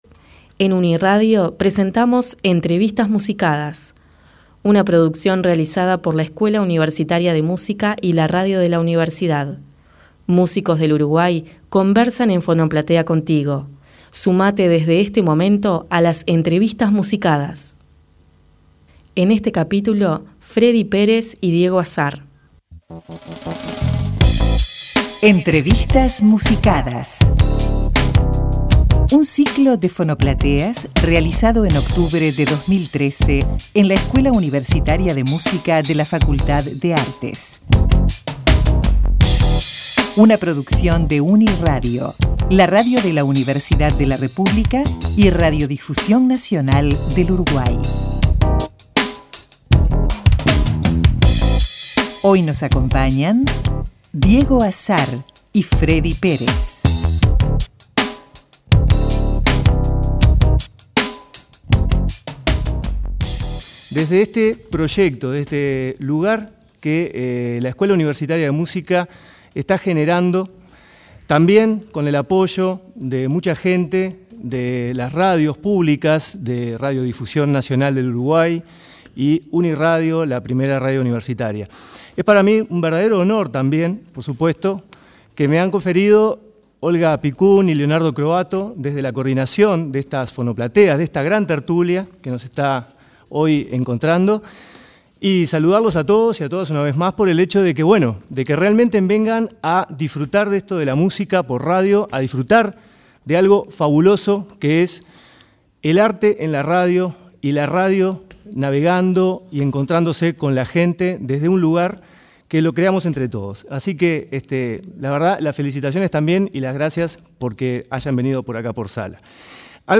La serie se compone de cuatro jornadas de fonoplatea radiofónica que se realizaron durante 2013 en el Auditorio de la Facultad de Artes.